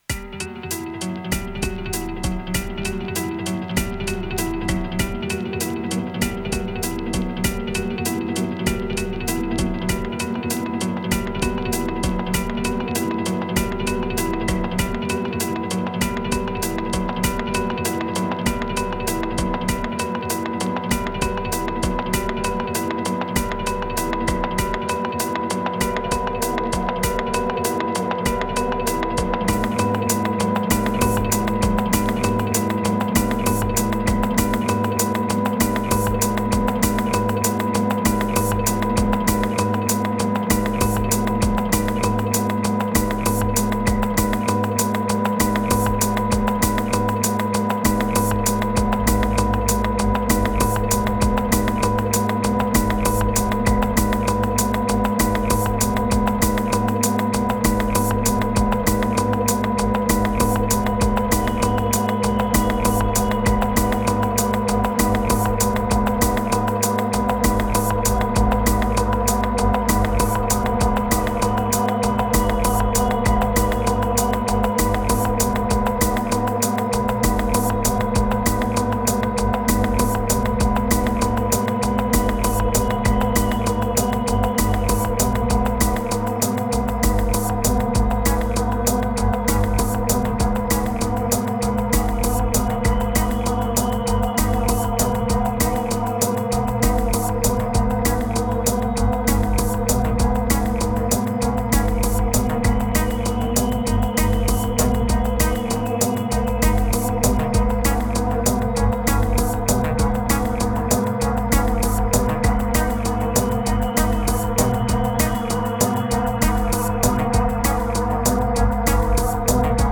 Laidback after the storm, possibly, maybe dark.